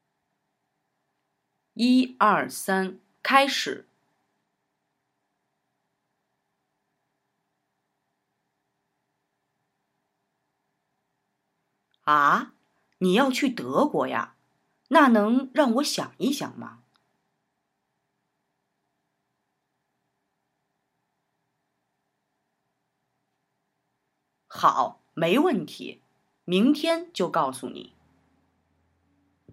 In Übung 2 sprecht ihr Part B, in Übung 3 übernehmt ihr Part A (Damit ihr wisst, wann es losgeht, gibt es hier zum Einstieg noch ein kurzes Signal.).
Anfangs könnt ihr Zeichen und Pinyin als Stütze nehmen, später könnt ihr versuchen, den Dialog mit unserer Sprecherin als digitalem Gesprächpartner frei nachzusprechen.
Übung 3: Sprich Part A!